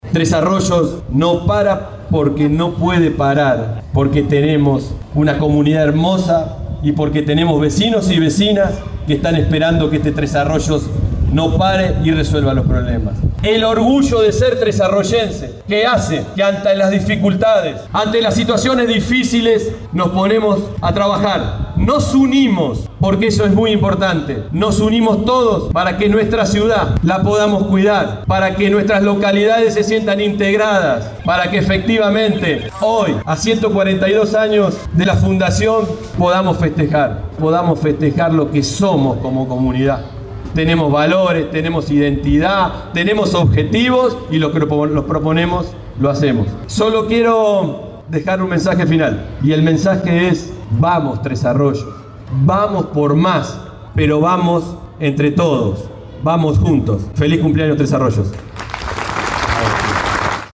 Se realizó el acto oficial del 142º Aniversario de Tres Arroyos
Este viernes 24 de abril, en el escenario Juan Pesalaccia, se llevó a cabo el acto oficial conmemorativo de los 142º años cumplidos por la ciudad de Tres Arroyos.
PABLO-GARATE-ACTO.mp3